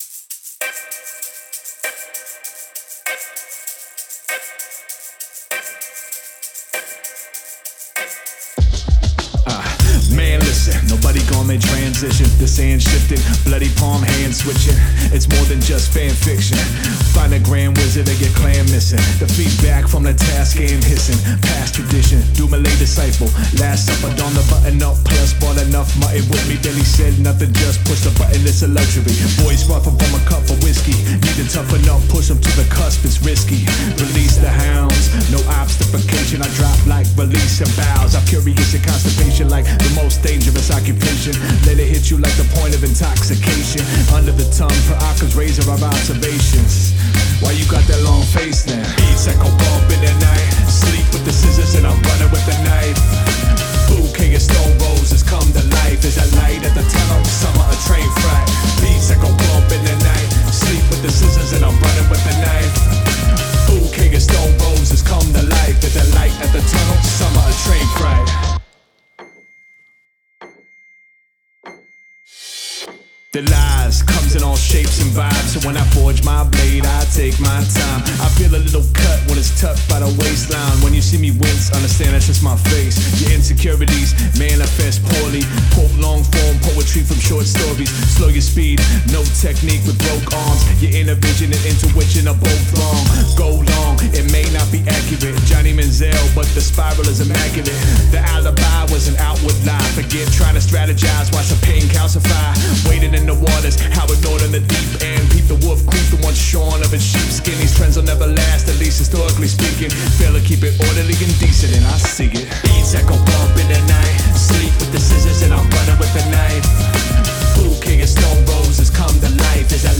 Habe versucht das ganze Oldschool klingen zu lassen...